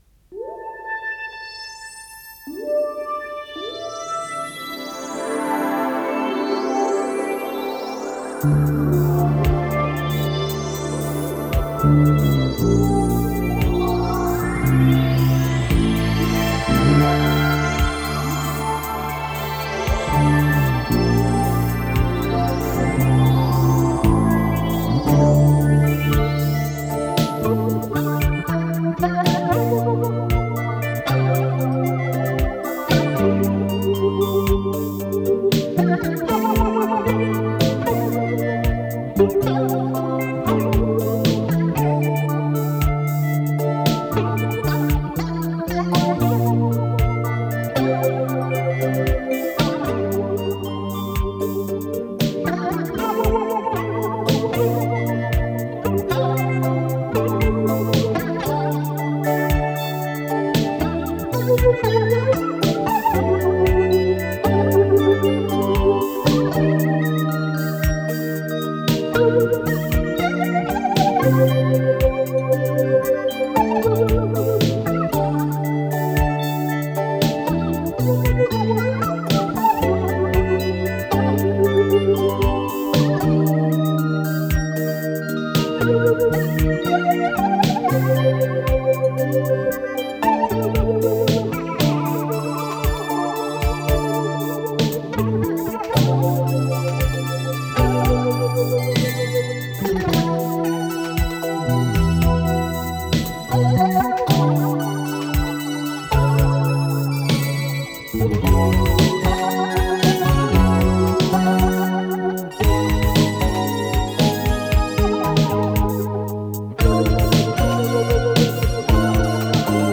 синтезатор
ВариантДубль моно